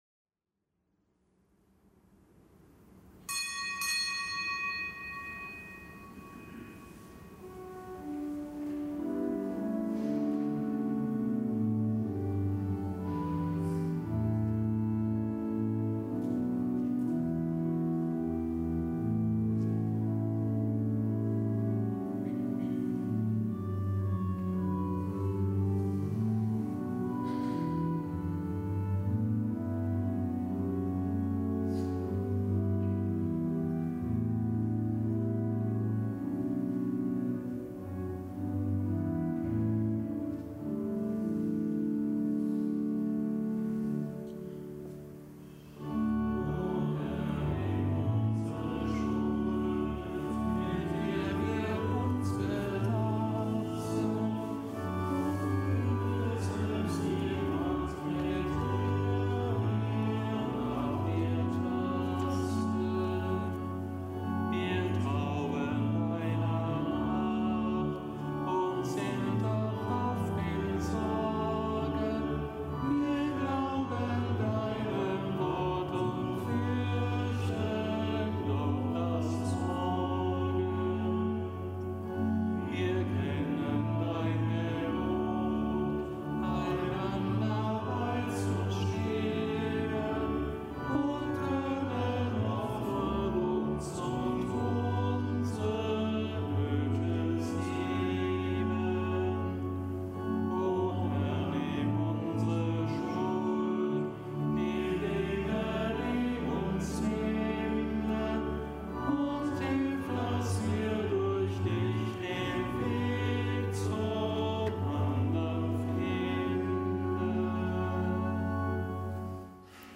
Kapitelsmesse aus dem Kölner Dom am Mittwoch der zweiten Fastenwoche.